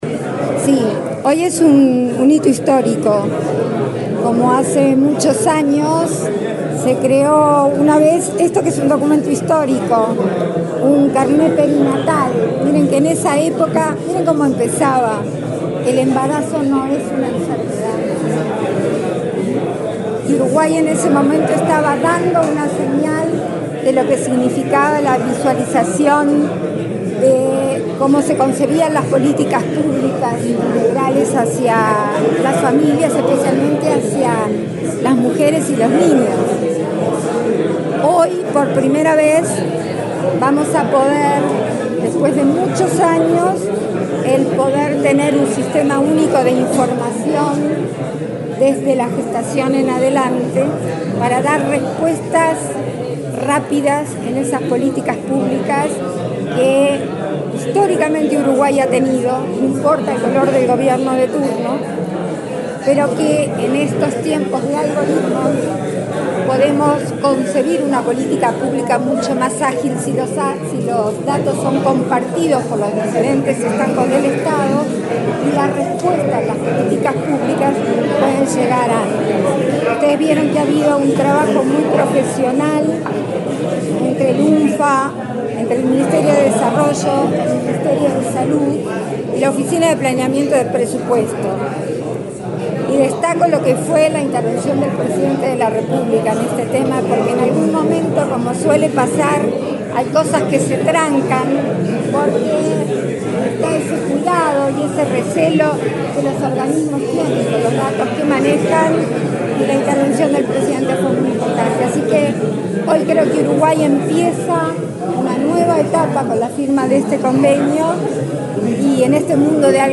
Declaraciones de la vicepresidenta, Beatriz Argimón
Declaraciones de la vicepresidenta, Beatriz Argimón 18/11/2024 Compartir Facebook X Copiar enlace WhatsApp LinkedIn La vicepresidenta, Beatriz Argimón participó, este lunes 18 en la sede del Ministerio de Desarrollo Social (Mides), en la presentación de avances del programa integral de primera infancia. Luego, dialogó con la prensa acerca de la importancia de esta temática.